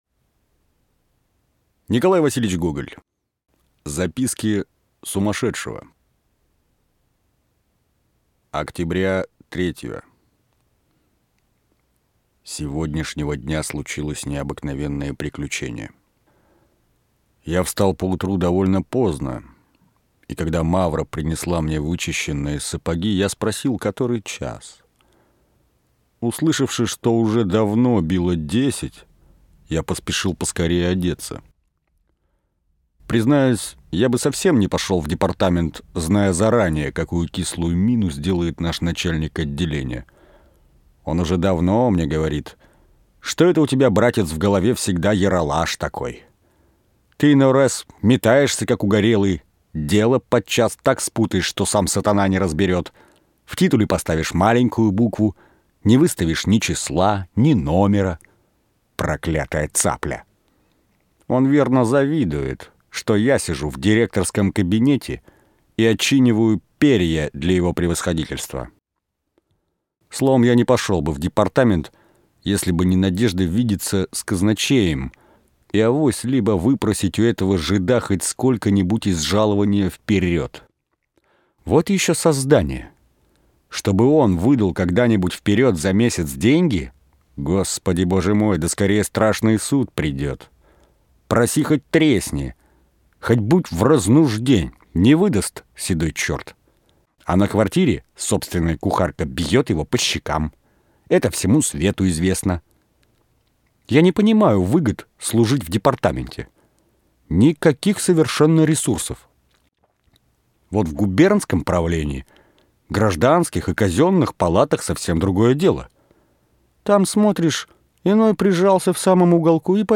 Аудиокнига Фантастические повести: Записки сумасшедшего. Портрет | Библиотека аудиокниг